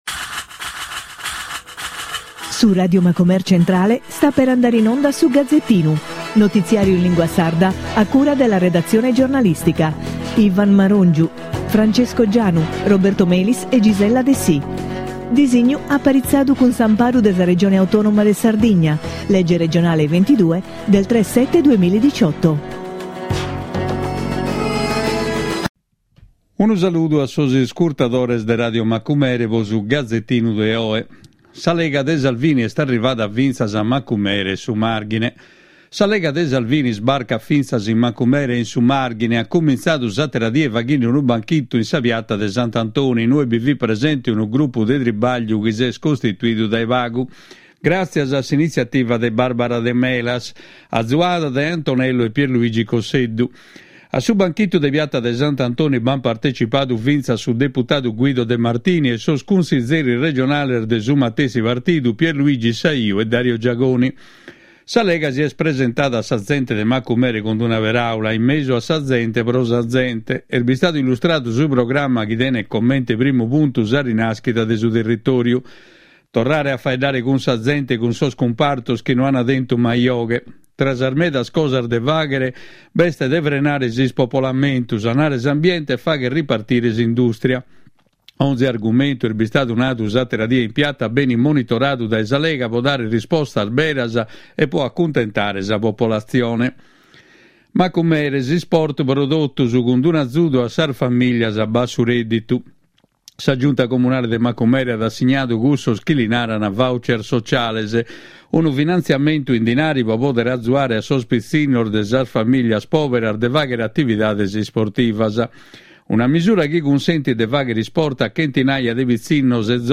Notiziario in lingua sarda con la consulenza di esperti. Le principali notizie nazionali e nel dettaglio quelle regionali con particolare riferimento all’attività socio economica e culturale della nostra isola con un occhio particolare al mondo dei giovani.